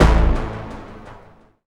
bseTTE52013hardcore-A.wav